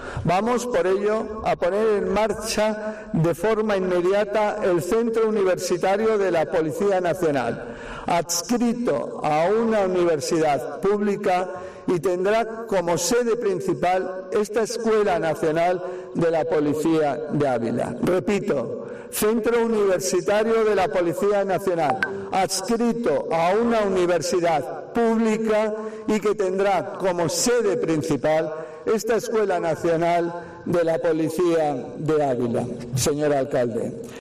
Ministro de Interior, Fernando Grande-Marlaska. Centro Universitario de la Policía Nacional